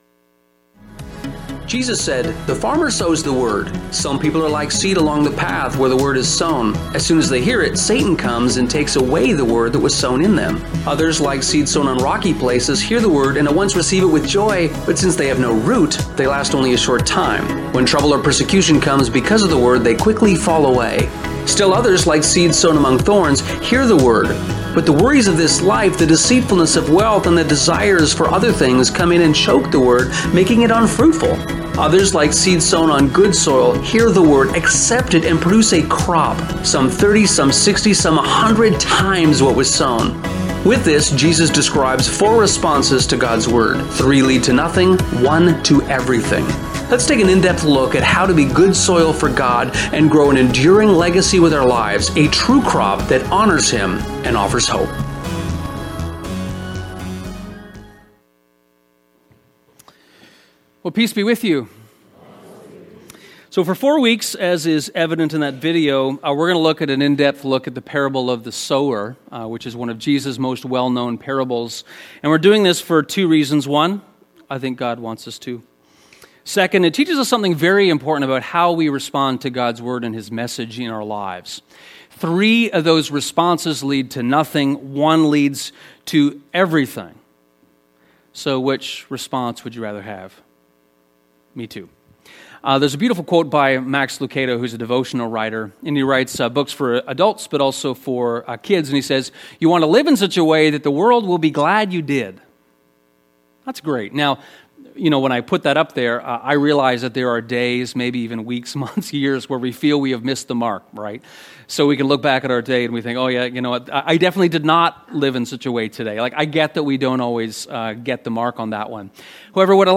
” The teaching was called “Cherishing the seed.”